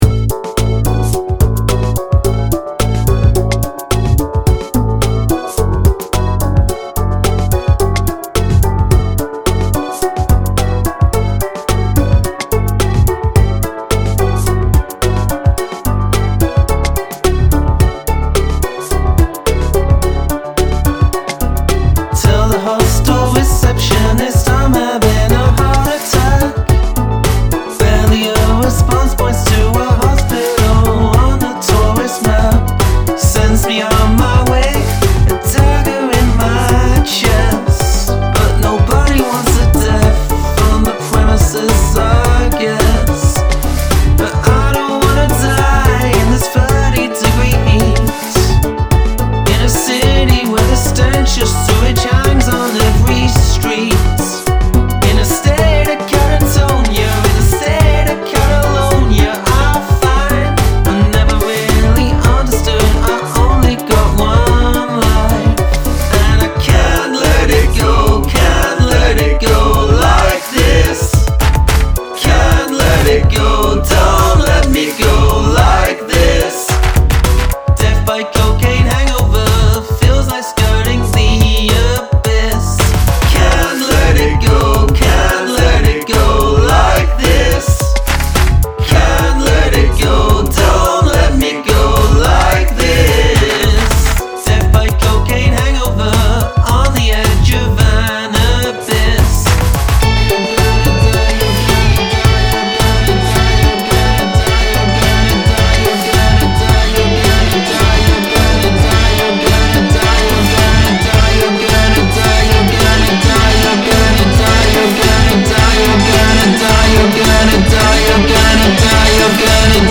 Strength: production, soundgarden-sounding leading guitar